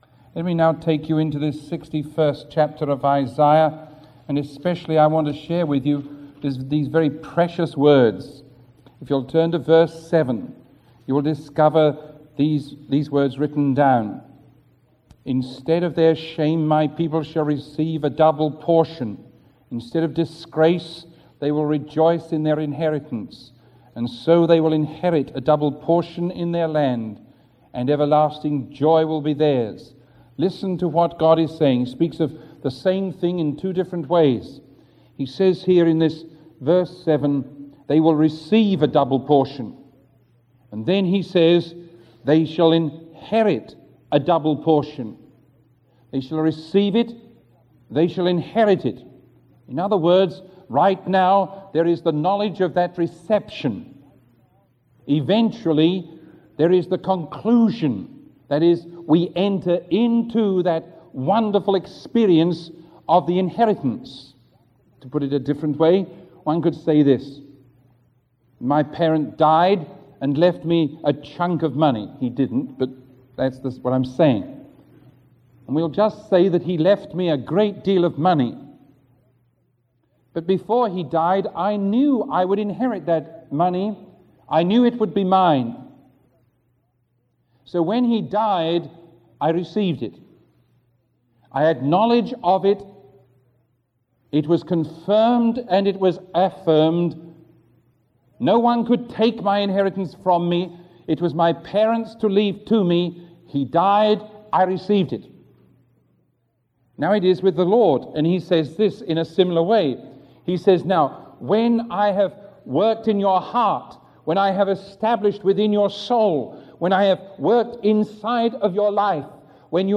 Sermon 0912A recorded on July 15